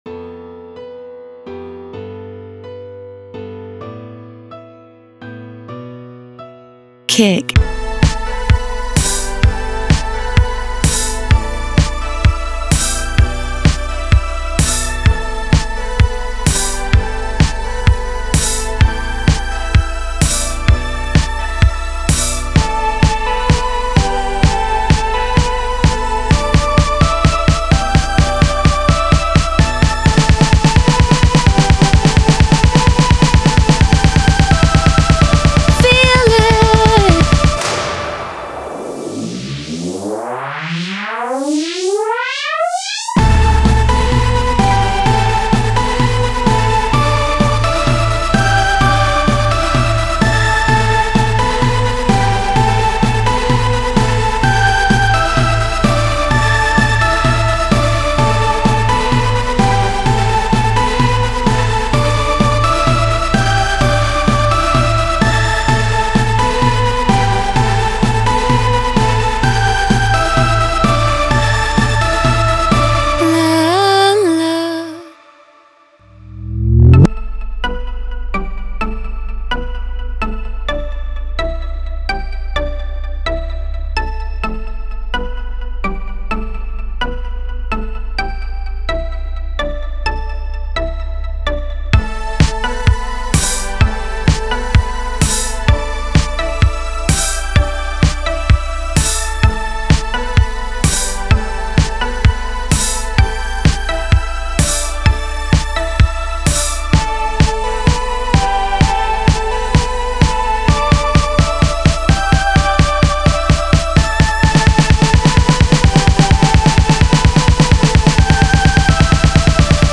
Home > Music > Electronic > Bright > Dreamy > Running